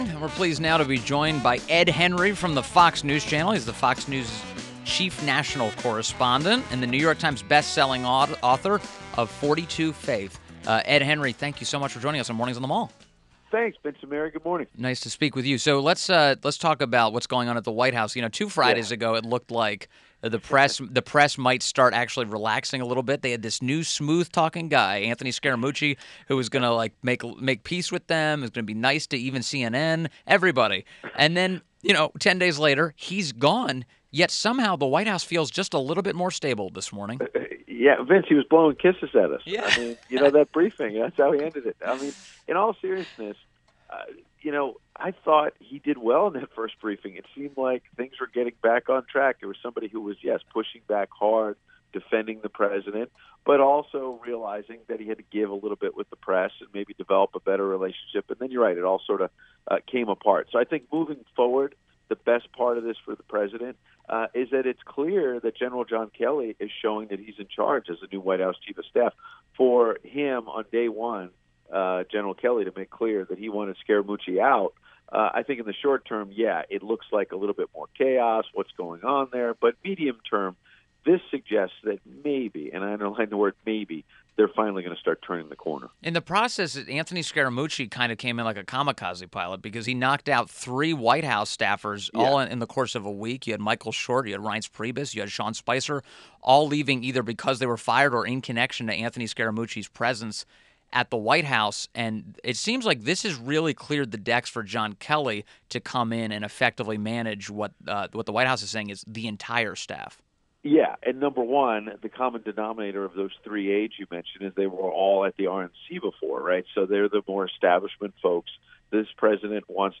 WMAL Interview - ED HENRY 08.01.17
INTERVIEW - ED HENRY - Fox News Chief National Correspondent, New York Times Bestselling Author "42 Faith"